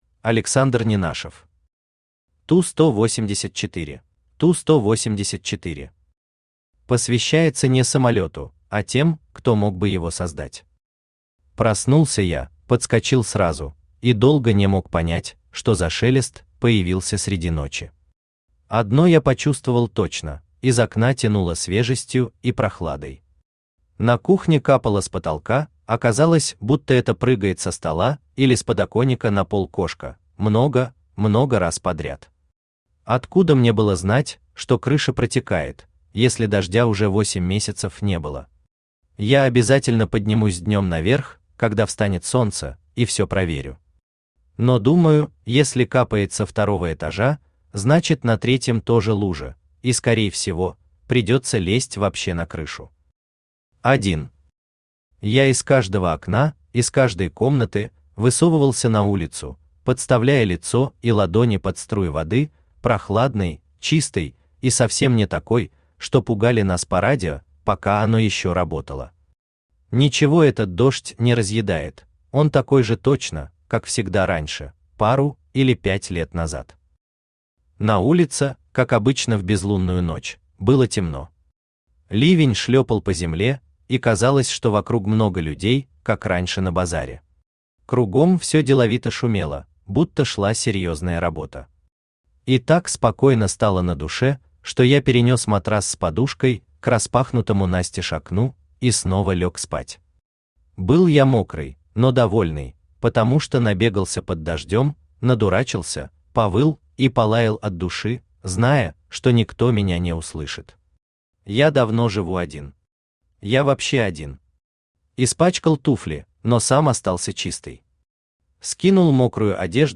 Аудиокнига ТУ-184 | Библиотека аудиокниг
Aудиокнига ТУ-184 Автор Александр Владимирович Ненашев Читает аудиокнигу Авточтец ЛитРес.